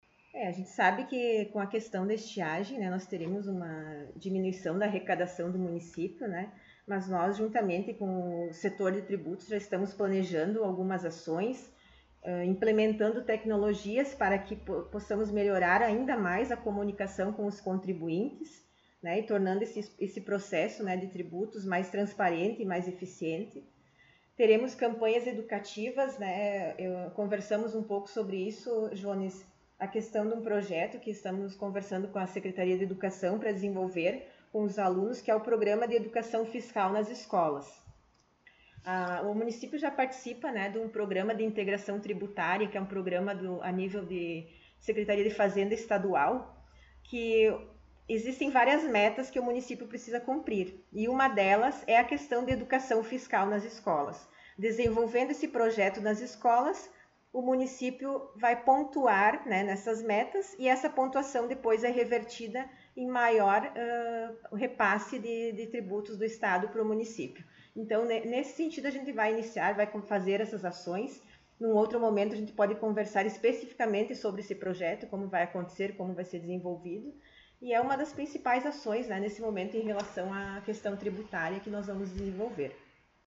Secretária Municipal de Administração e Fazenda concedeu entrevista
O Colorado em Foco esteve na Prefeitura, na sala da secretária, para sabermos um pouco mais da situação econômica do município e outros assuntos.